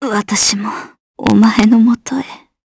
Valance-Vox_Dead_jp_b.wav